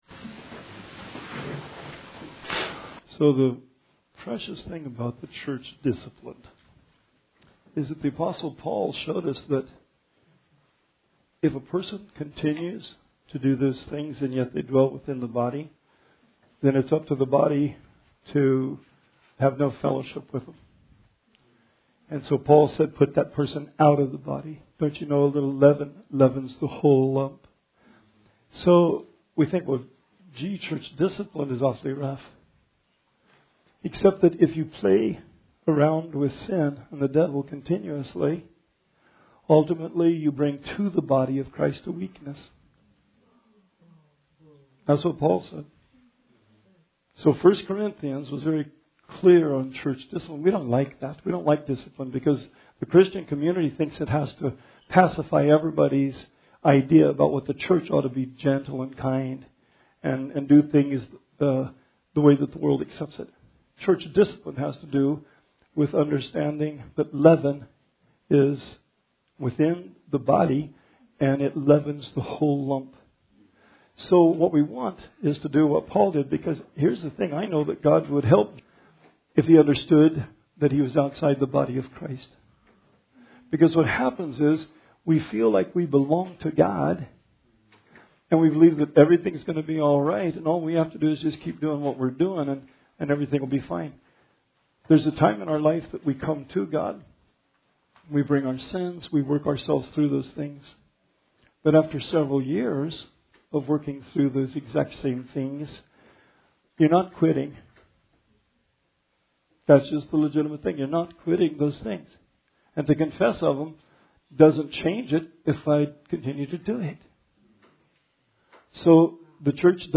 Bible Study 12/4/19